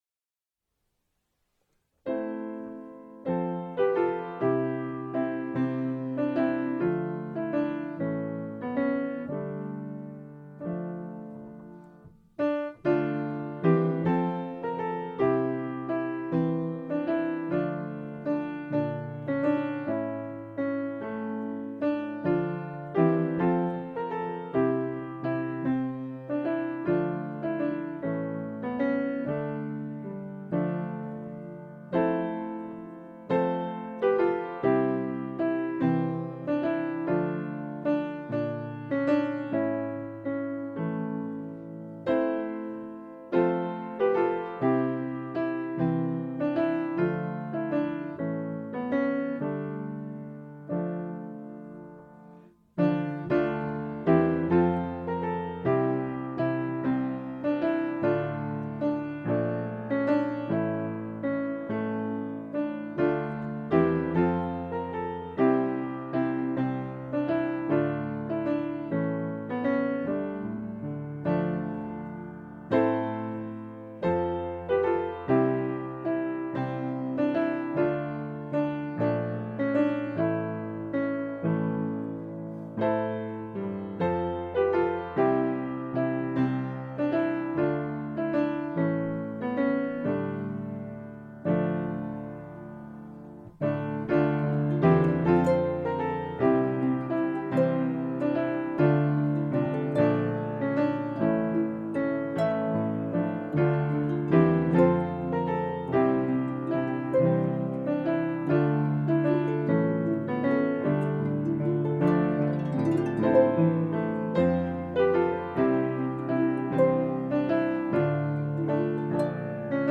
Here are Christmas songs for corporate singing.
harp last verse (3 verses: lowered key D minor)Download